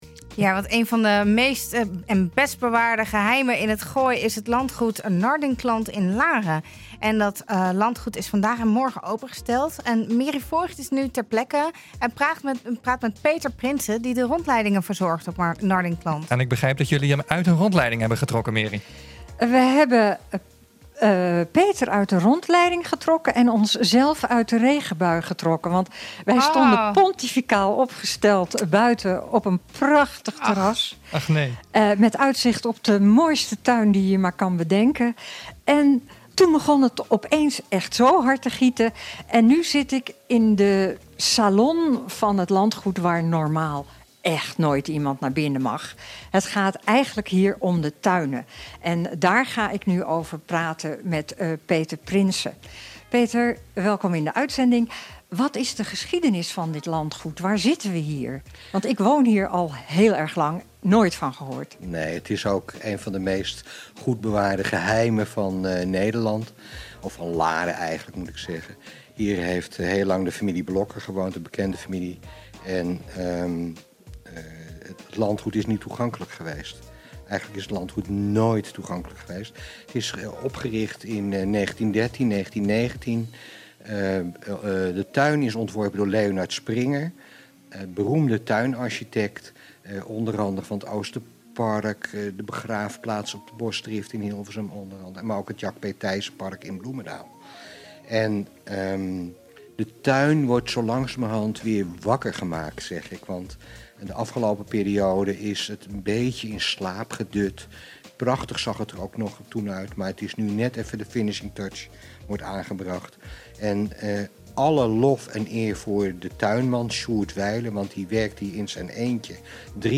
Een van de best bewaarde geheimen in het Gooi is het landgoed Nardinclant in Laren. En dat landgoed waren vanwege de Open Monumentendagen opengesteld.